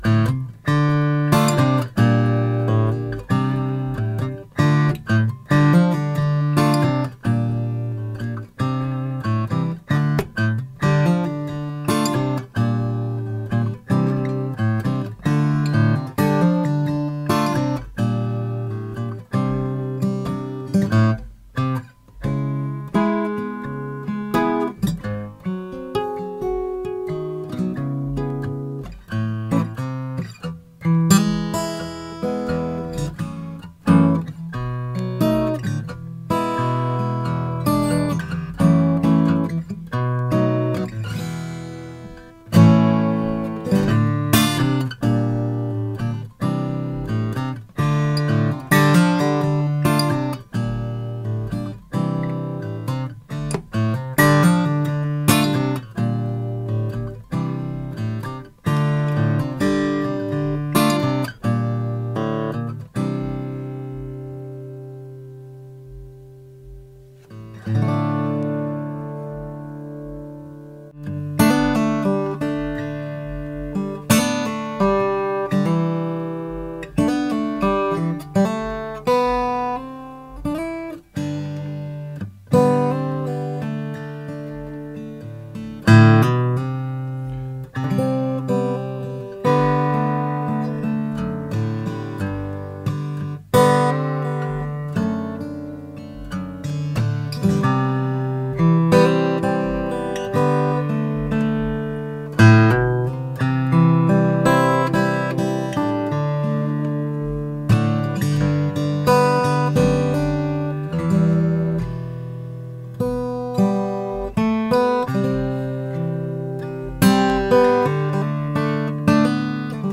She is equally at home fingerpicking or strumming big, brash chords. The combination of the Indian Rosewood with Sitka Spruce offers a rich tonal variation and lovely organic feel, it’s the perfect partner for players who need a guitar that can compete right across the board.
The thicker and wider neck provided more structural stability while also increasing the guitar’s sustain and brightness.